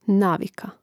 nàvika navika